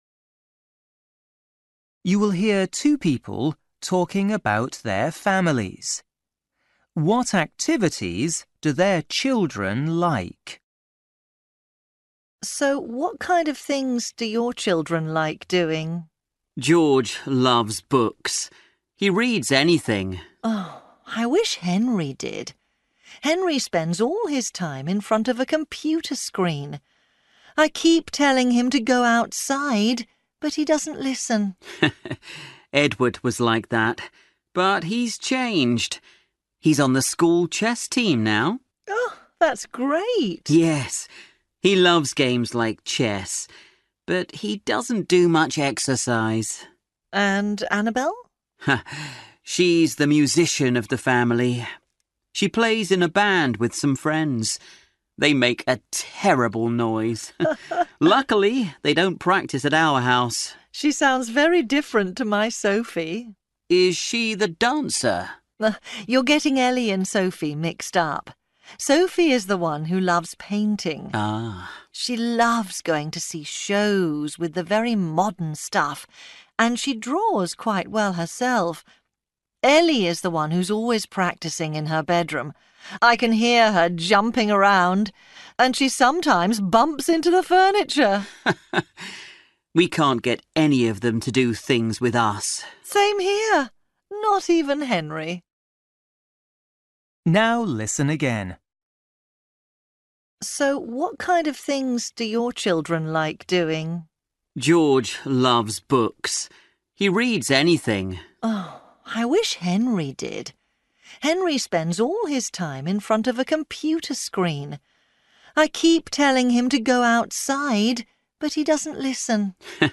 You will hear two people talking about their families.